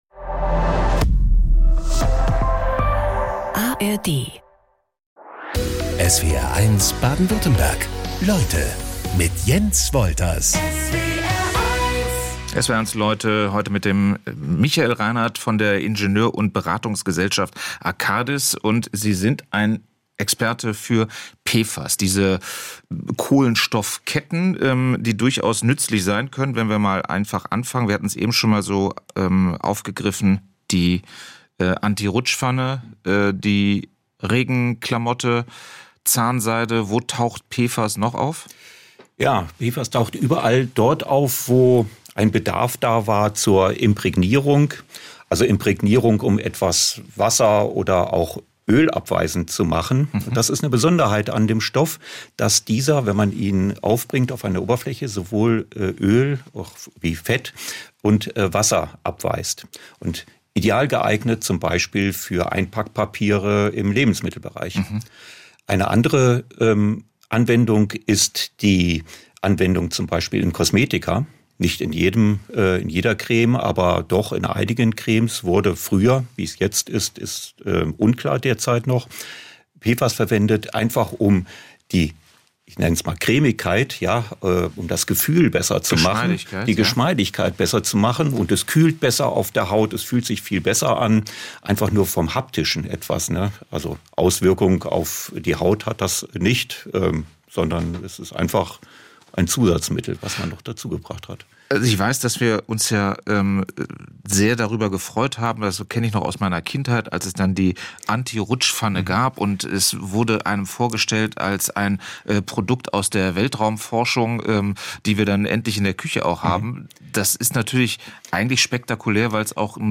Jeden Tag talken unsere SWR1 Leute-Moderator:innen in Baden-Württemberg und Rheinland-Pfalz mit interessanten und spannenden Gästen im Studio.